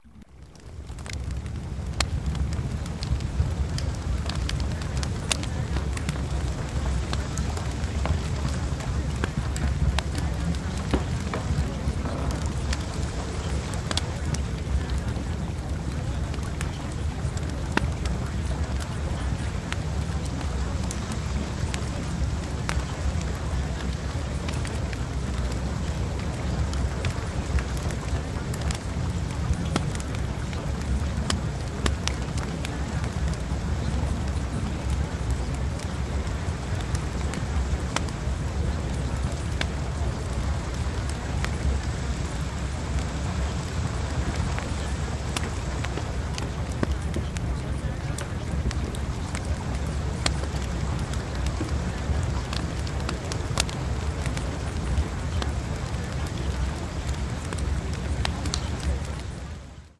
The Desired Face Subliminal audio program comes with a variety of 1-hour MP3 tracks to choose from, including calming background music and nature sounds, making it perfect for meditation and relaxation.
DESIRED-FACE-Fireplace-Sample.mp3